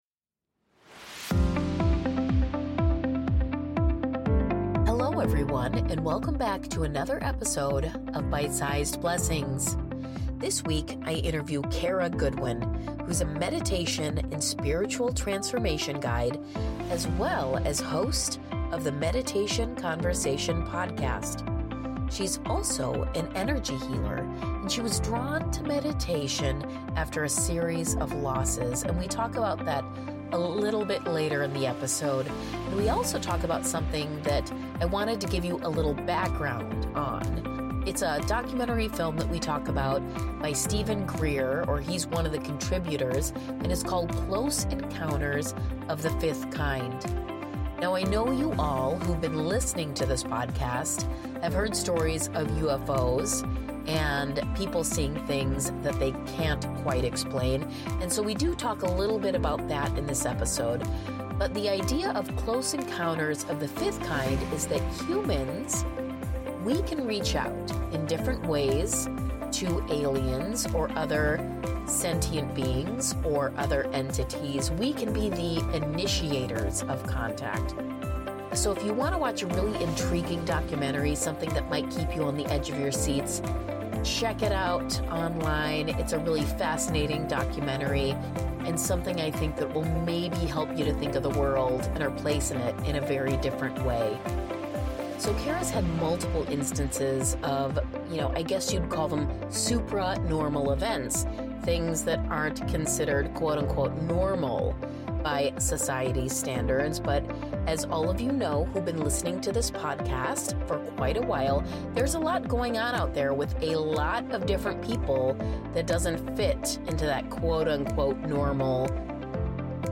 180: The Interview